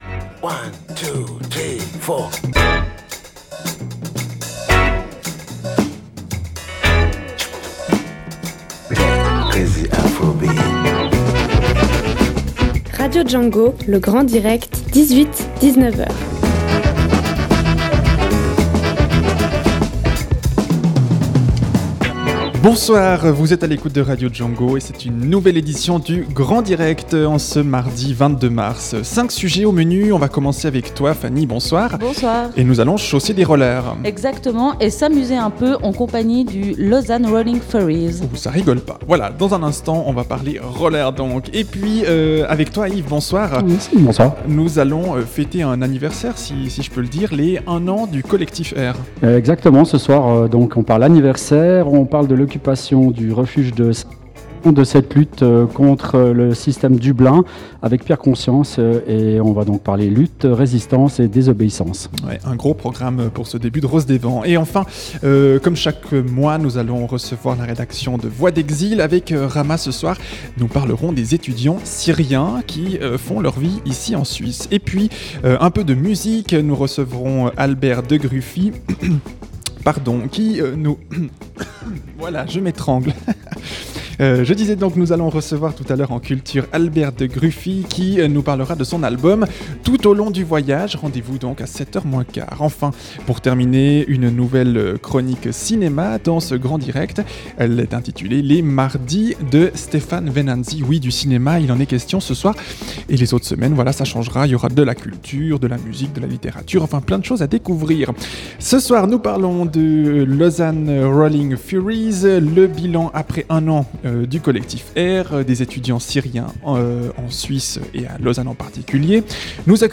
Le Grand direct de Radio Django, c’est ce mardi 5 sujets: